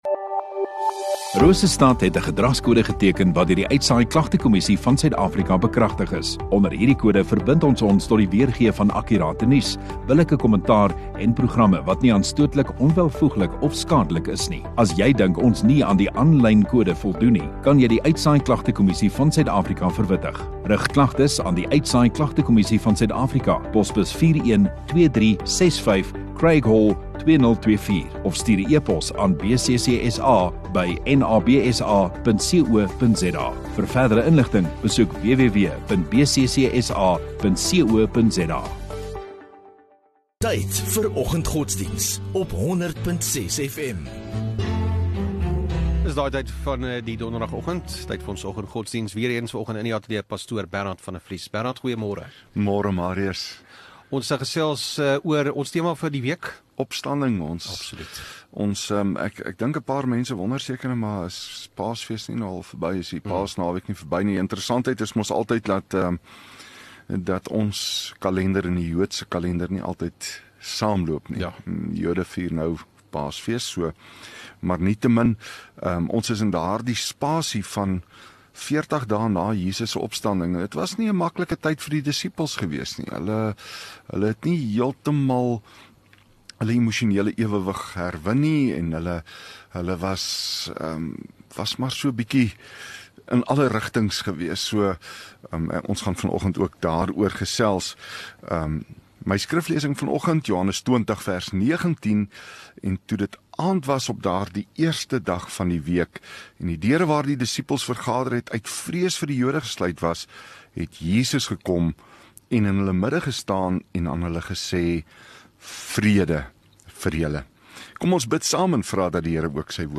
25 Apr Donderdag Oggenddiens